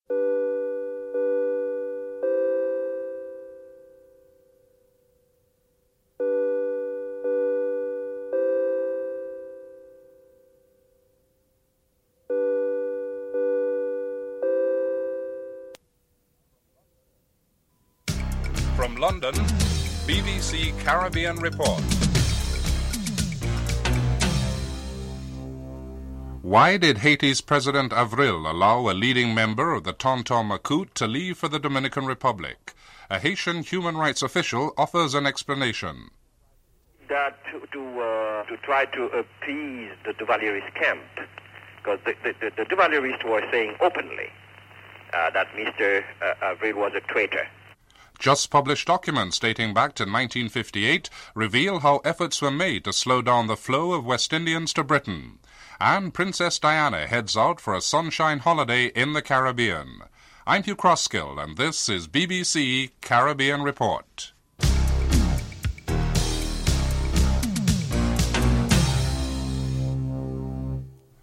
1. Headlines (00:00-01:10)